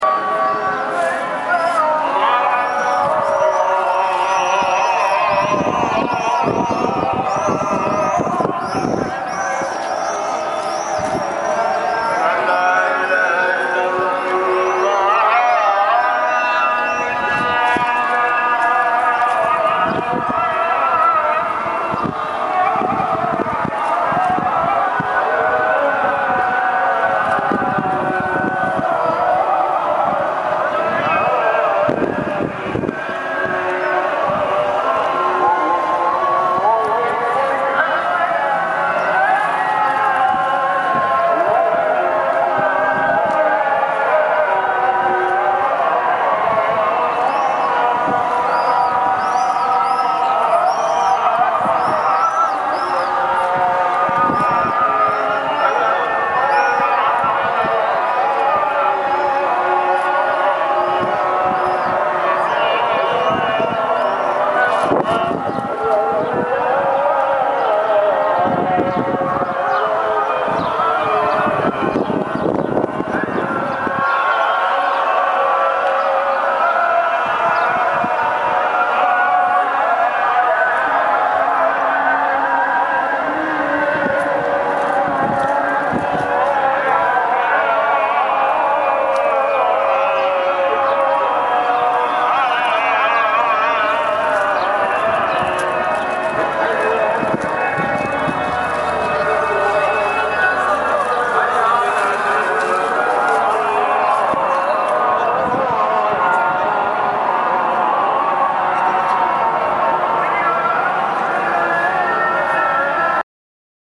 (After we visited the Grand Bazaar – see separate post – we climbed to a rooftop where we were surrounded by mosques and heard the evening call from all directions at once.
The Evening Call in Istanbul.  Our guide explained this is the sound of over 30 mosques – in most of Instanbul you would only be hearing a few of these not all of them.)